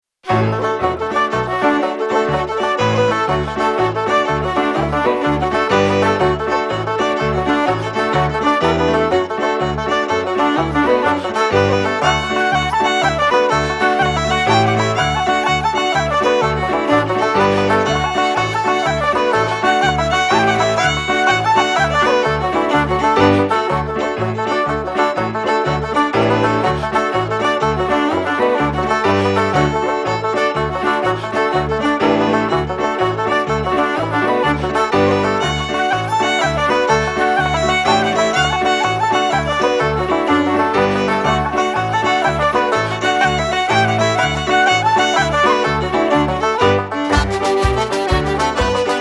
Slip Jigs
accordion
flute
fiddle
banjo
piano
drums
Hardiman's are a short but fabulous set of slip jigs